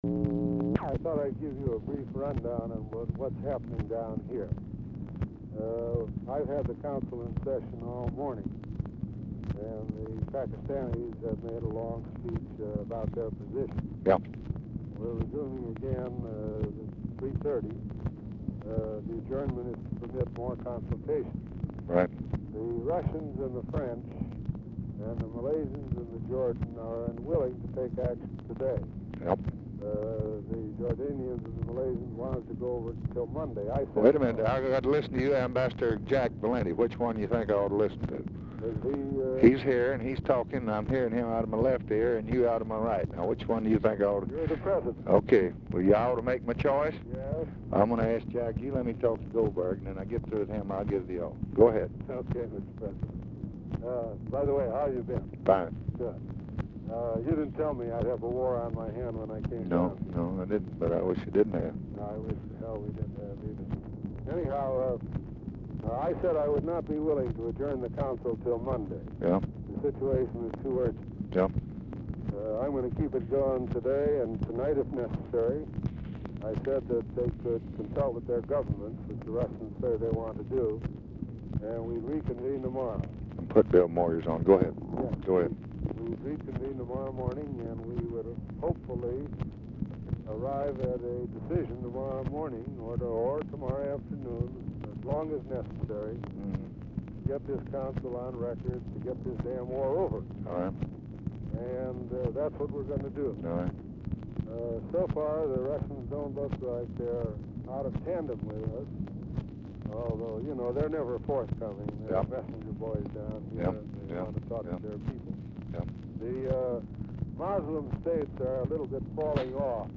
Telephone conversation # 8885, sound recording, LBJ and ARTHUR GOLDBERG, 9/18/1965, 1:27PM | Discover LBJ
RECORDING STARTS AFTER CONVERSATION HAS BEGUN; LBJ IS MEETING WITH BILL MOYERS AT TIME OF CALL; CONVERSATION BRIEFLY INTERRUPTED BY CONVERSATION BETWEEN UNIDENTIFIED FEMALE, MALE; CONTINUES ON NEXT RECORDING
Format Dictation belt
Specific Item Type Telephone conversation